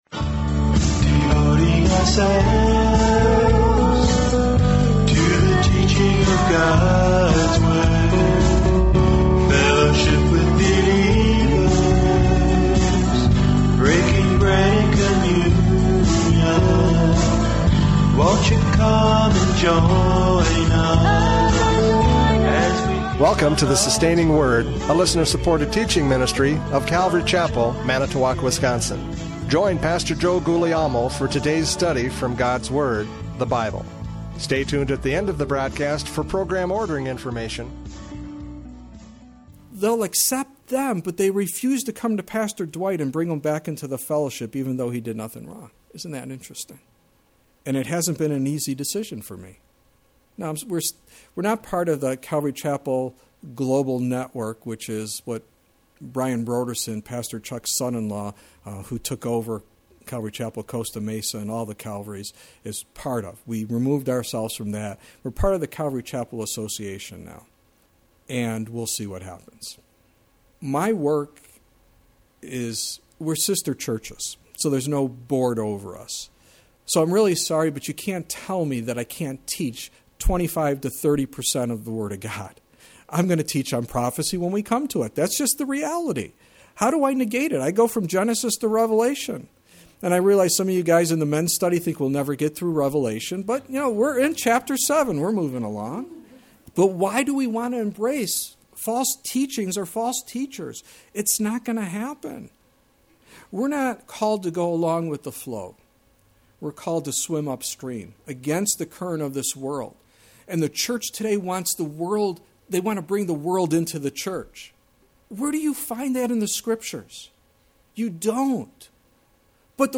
Radio Studies Passage: Judges 1:21-36 Service Type: Radio Programs « Judges 1:21-36 Preparing for Failure!